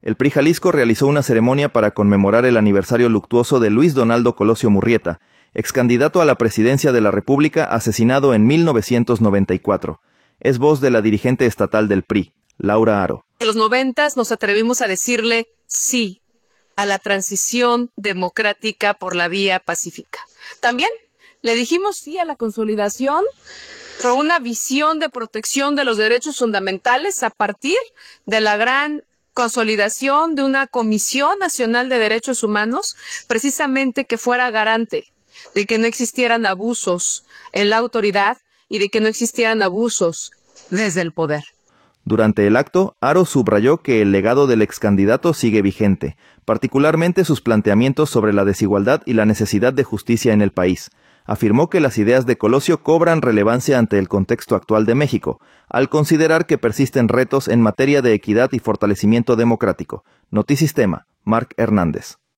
El PRI Jalisco realizó una ceremonia para conmemorar el aniversario luctuoso de Luis Donaldo Colosio Murrieta, excandidato a la Presidencia de la República asesinado en 1994.